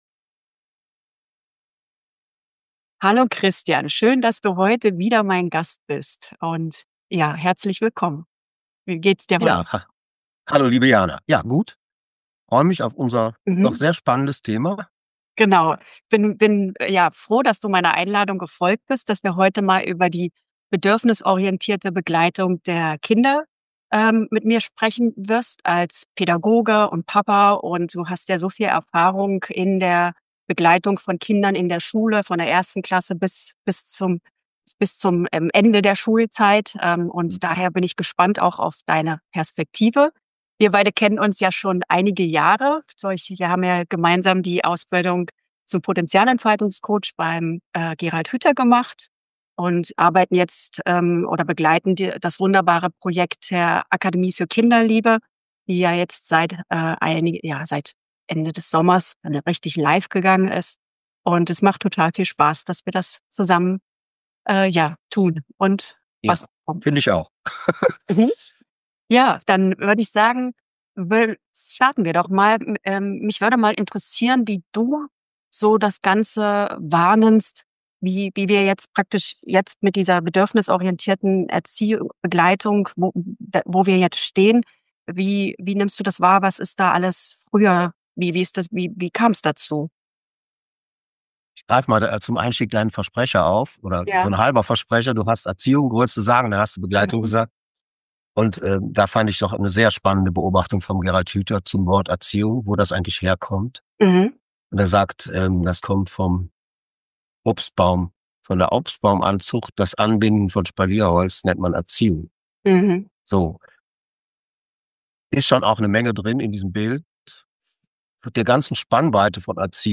Ein Gespräch voller Klarheit und Wärme.